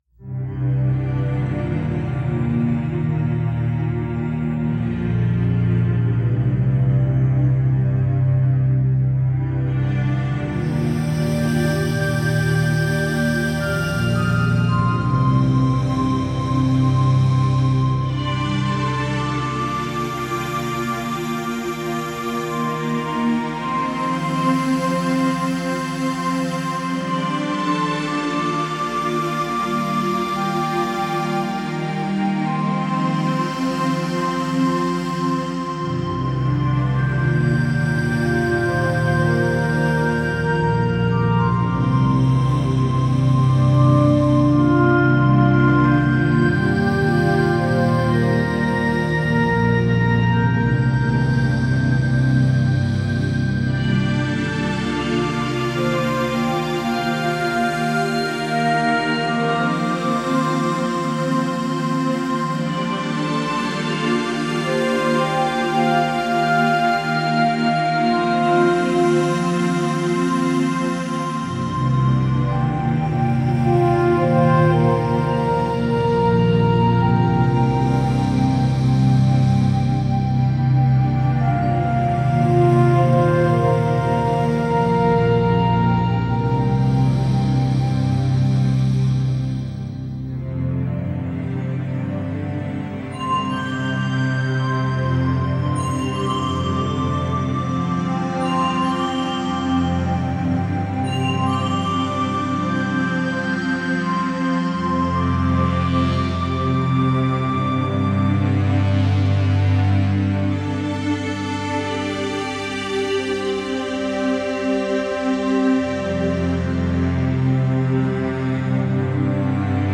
betoverende en emotionele muziek